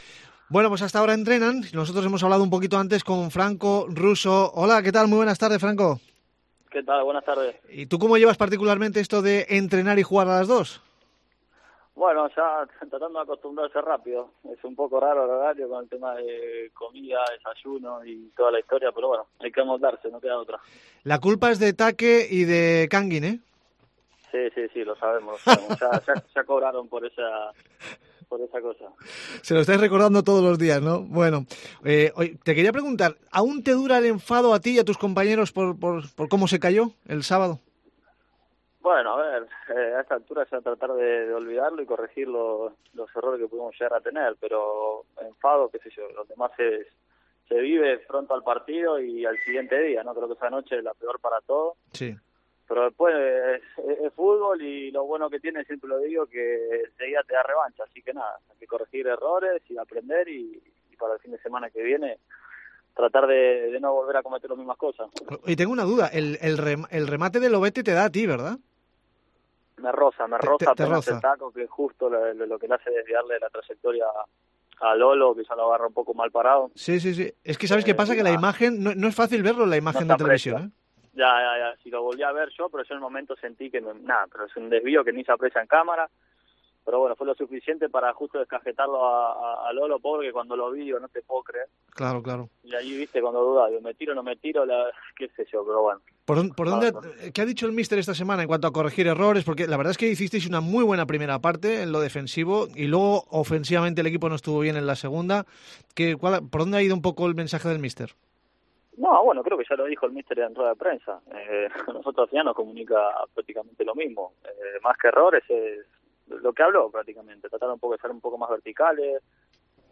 El central argentino analiza en esta entrevista la derrota en San Sebastián y el encuentro ante el Valencia.